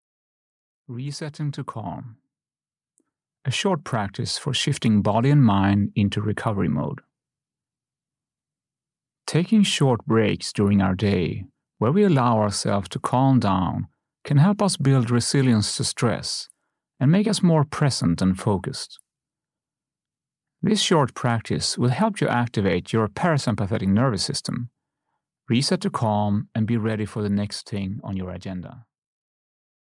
Audio knihaResetting to Calm (EN)
Ukázka z knihy